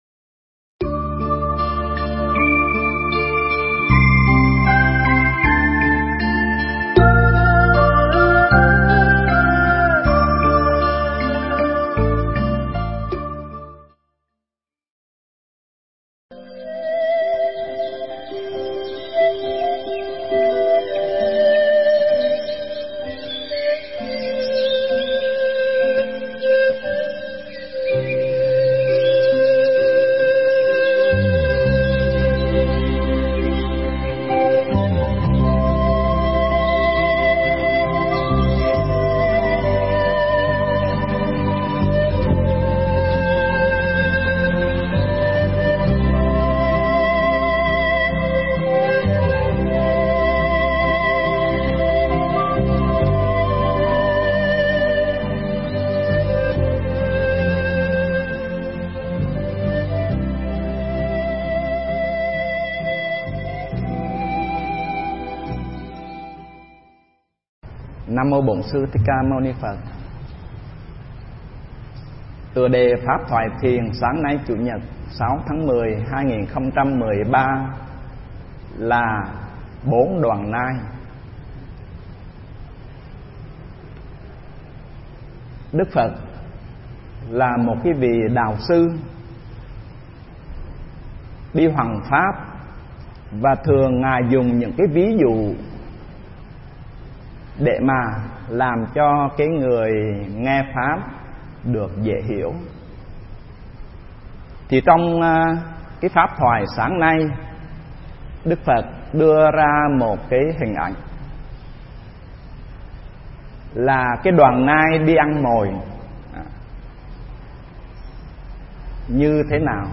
Nghe Mp3 thuyết pháp Bốn Đoàn Nai